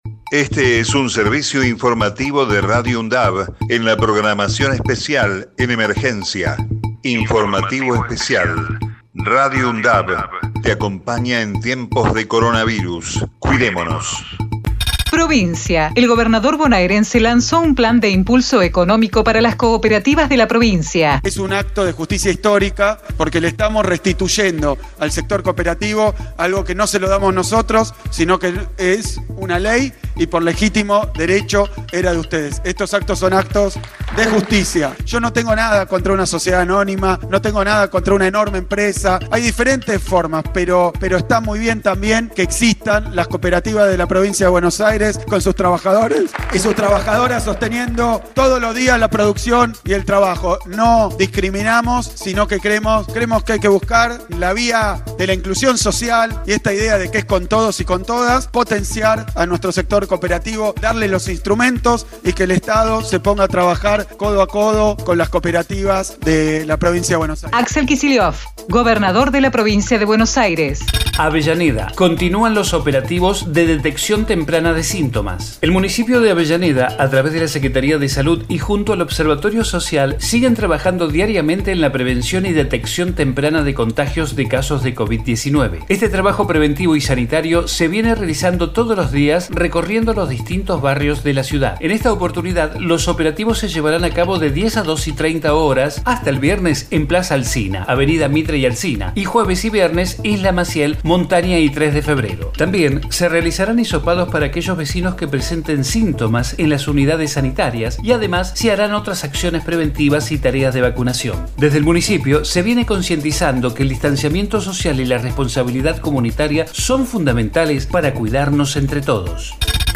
COVID-19 Informativo en emergencia 28 de octubre 2020 Texto de la nota: Este es un servicio informativo de Radio UNDAV en la programación especial en emergencia.